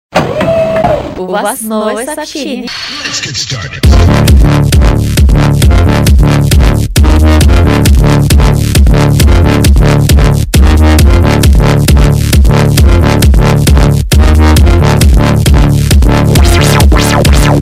Басс